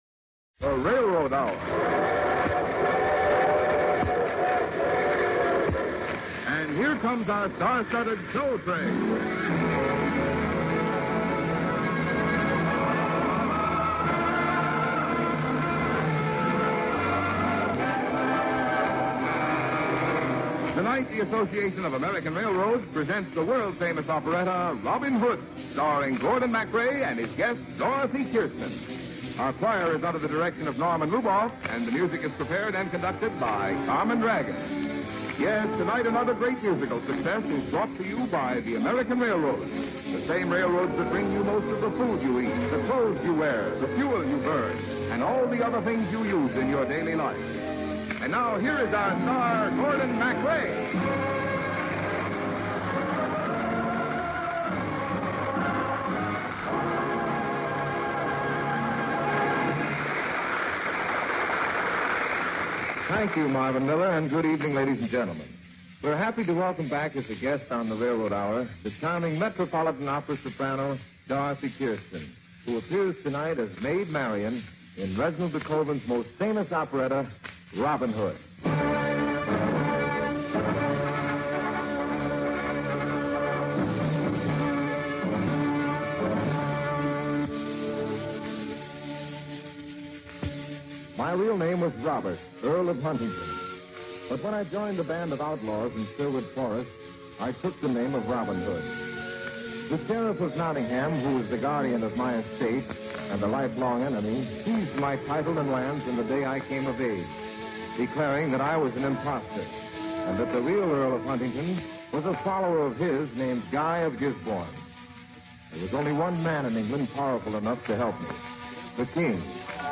musical dramas and comedies